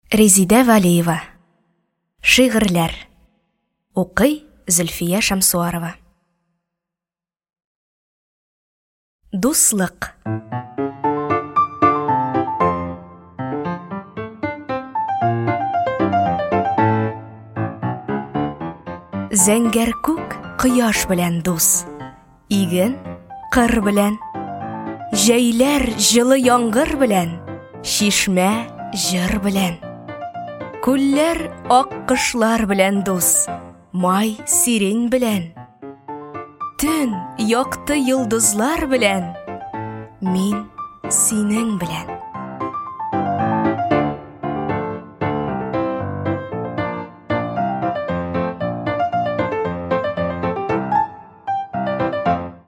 Аудиокнига Шигырьләр | Библиотека аудиокниг